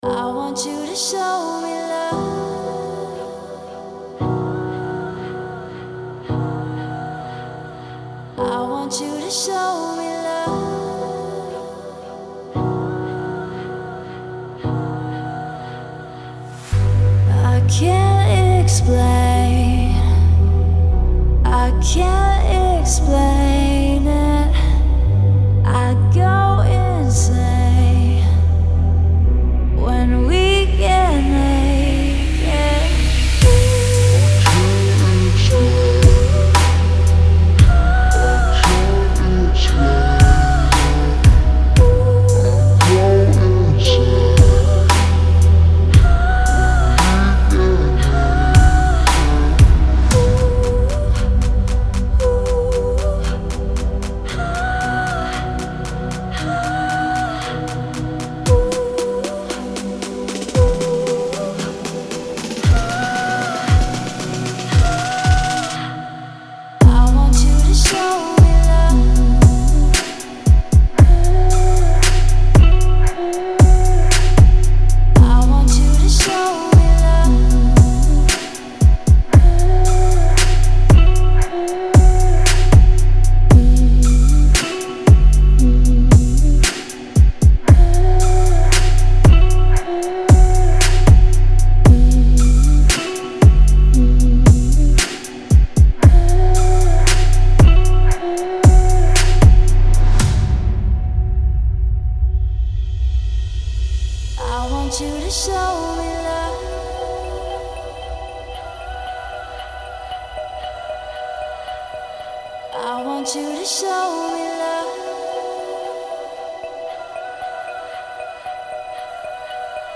это энергичный трек в жанре хаус